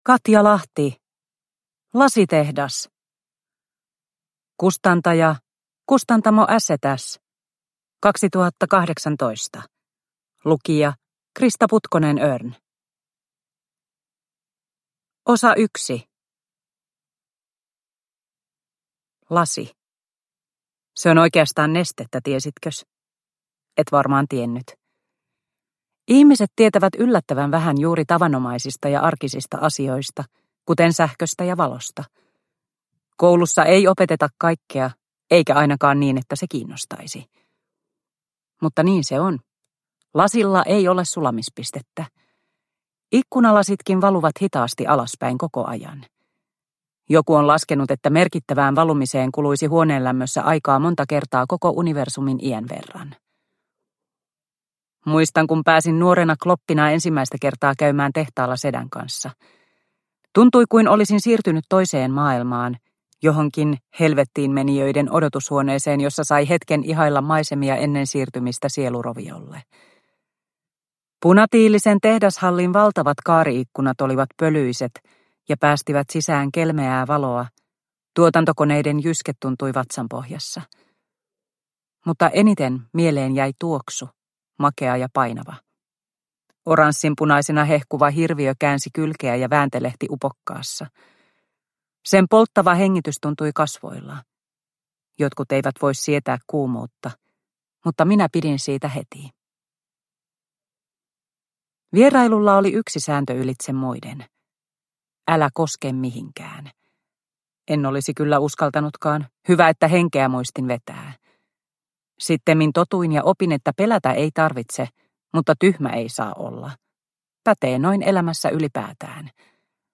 Lasitehdas – Ljudbok – Laddas ner